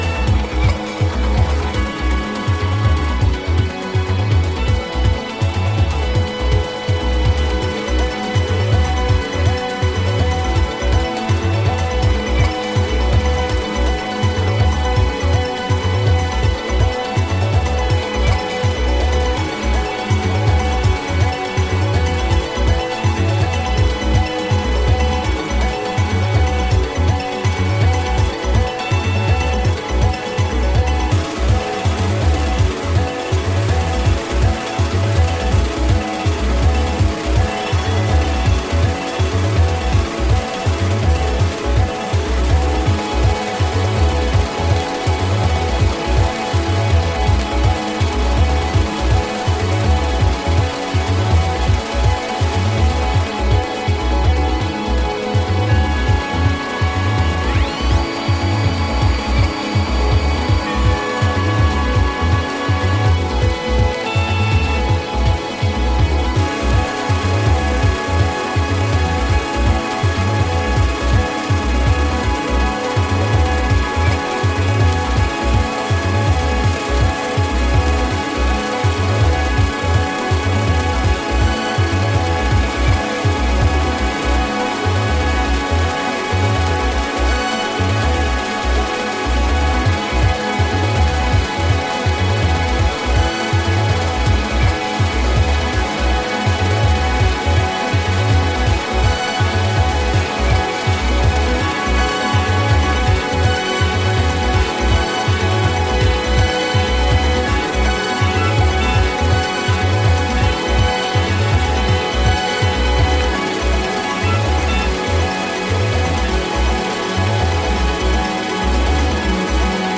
バウンシーなシャッフルビートと荘厳なフレージングが始まりの予感をもたらす